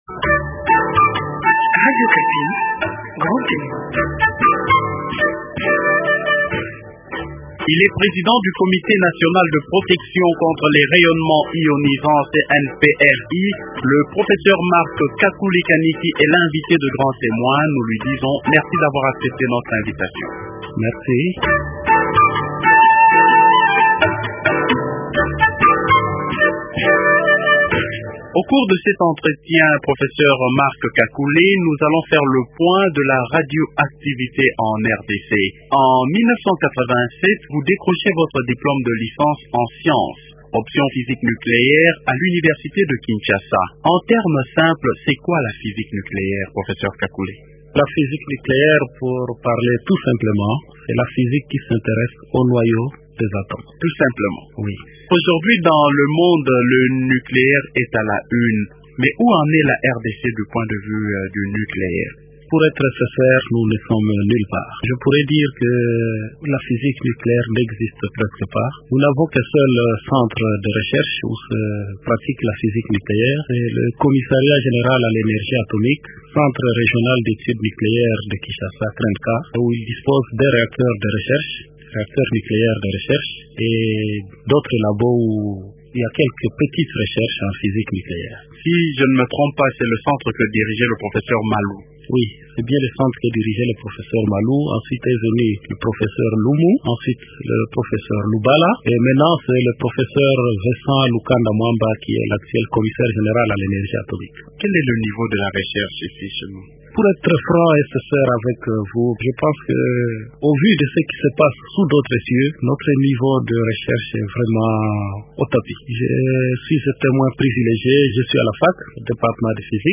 Le professeur Marc Kakule Kaniki est Président du Comité National de Protection contre les Rayonnements Ionisants (C.N.P.R.I.).